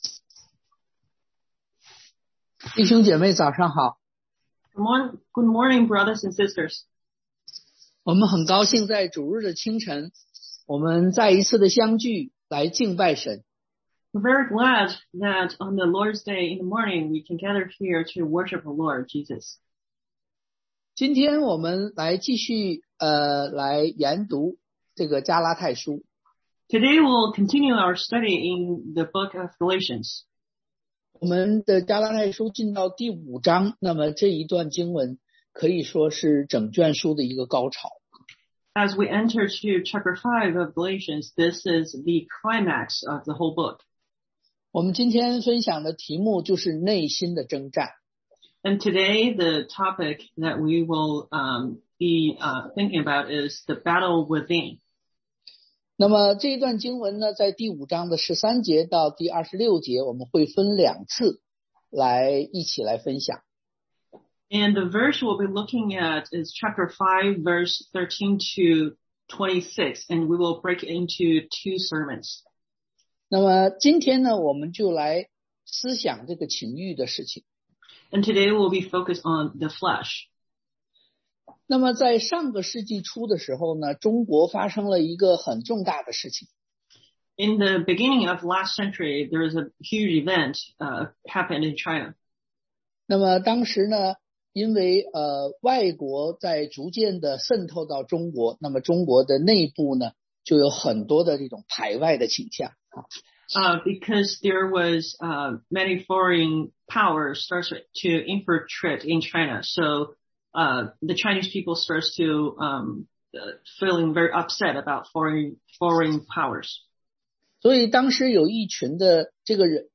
Gal 5:13-26 Service Type: Sunday AM The Battle Within 內心的爭戰
sermon.mp3